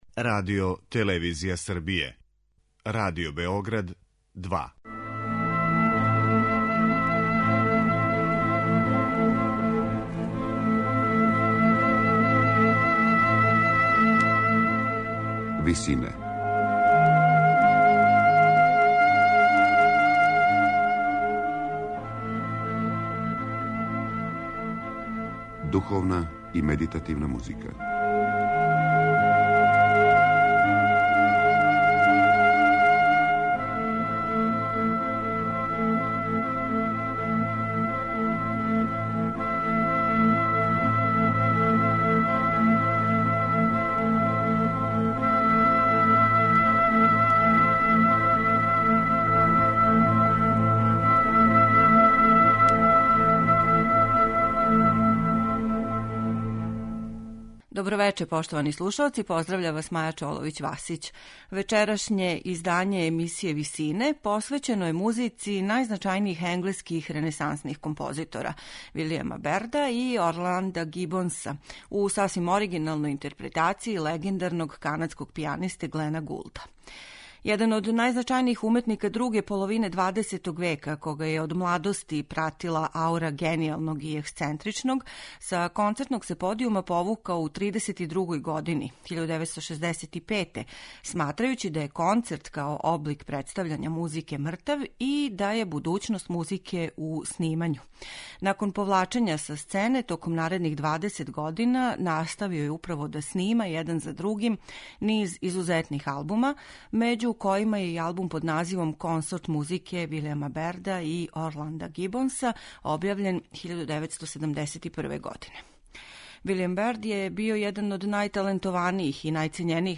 славног канадског пијанисте
композиције за клавсен